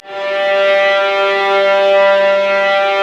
Index of /90_sSampleCDs/Roland LCDP13 String Sections/STR_Violas FX/STR_Vas Sul Pont